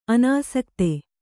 ♪ anāsakte